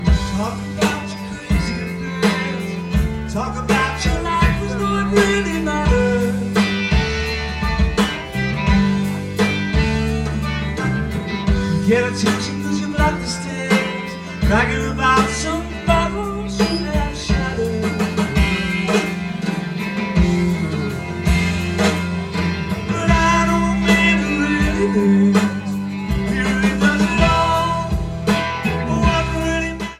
Comments: Very good mono soundboard recording*.